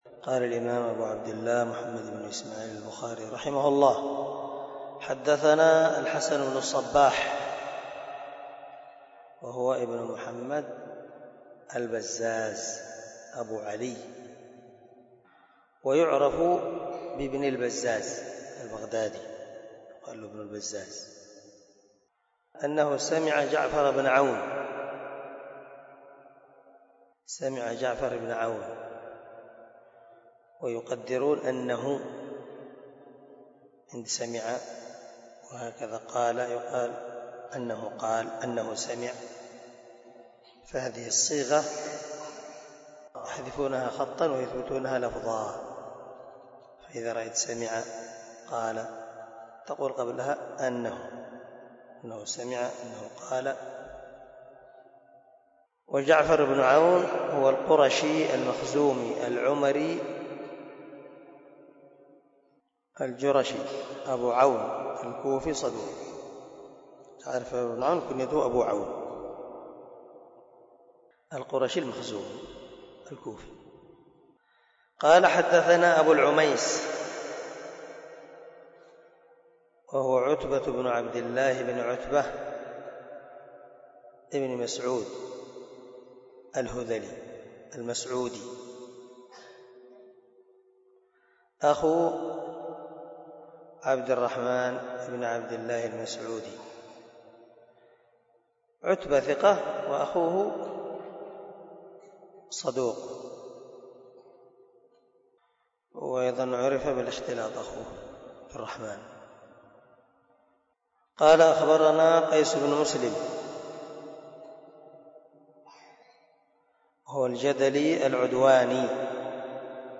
044الدرس 34 من شرح كتاب الإيمان حديث رقم ( 45 ) من صحيح البخاري